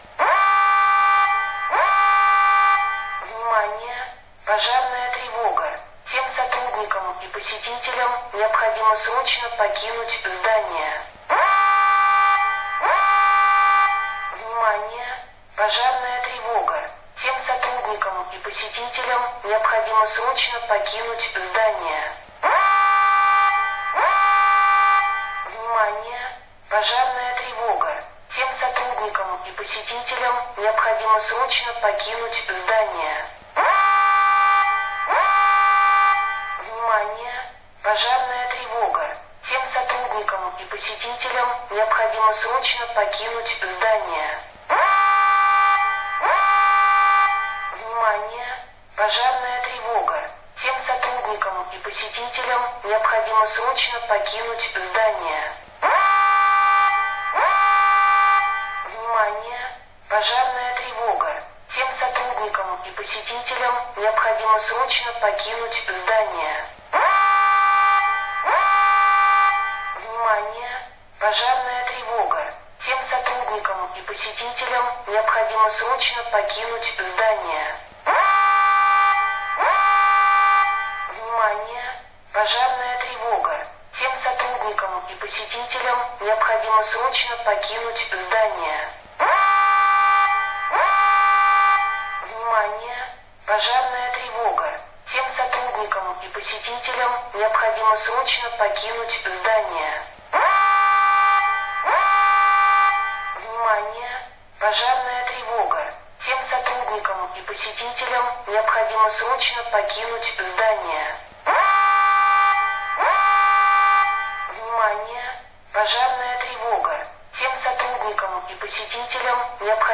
Звуки датчика дыма
На этой странице собраны звуки датчика дыма — от резкого тревожного сигнала до прерывистого писка.